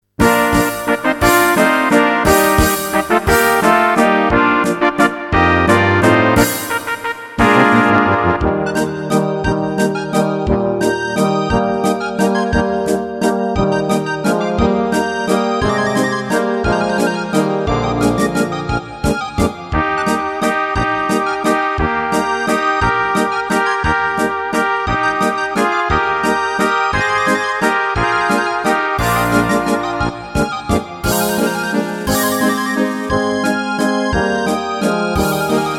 - polka
Karaoke